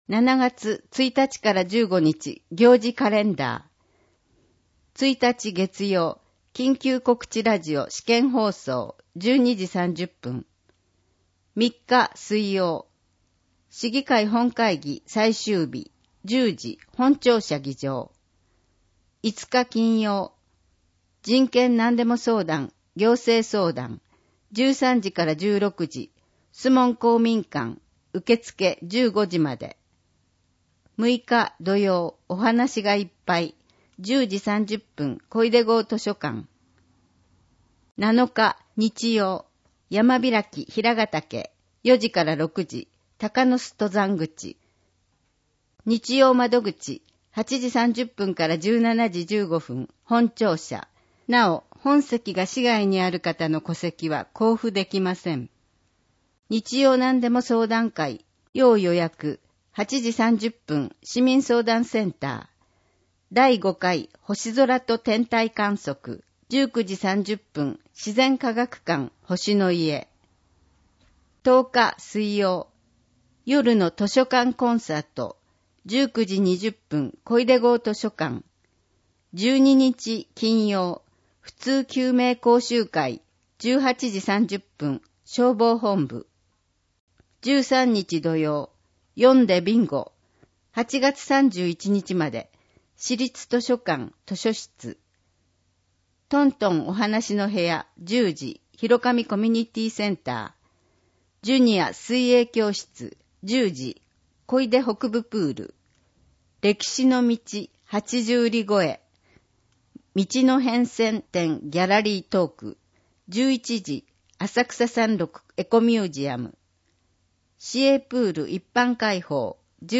字を読むことが困難な視覚障害者や高齢者や、市報を聞きたい方のために、「魚沼音声訳の会」のご協力により市報うおぬま音声版（ＭＰ3）をお届けします。